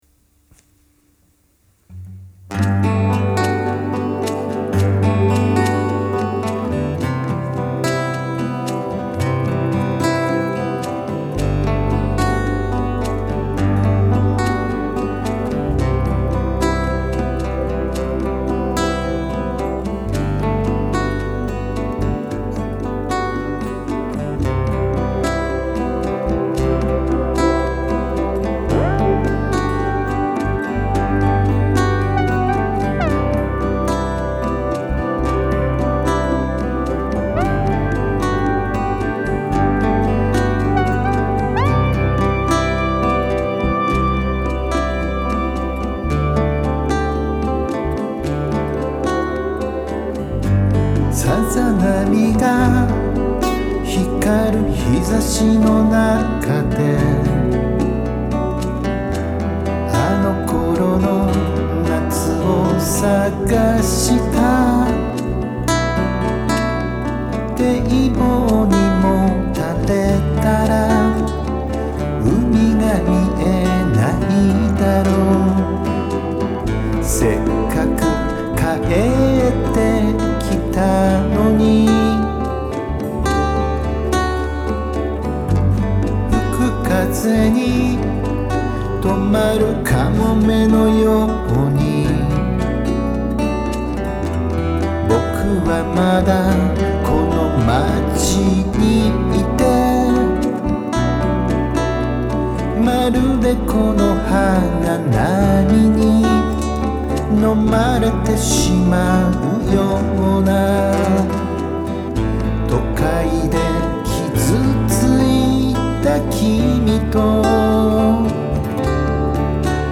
カバー曲　　青春時代の曲です
D-28 ゴダンのギターで製作しました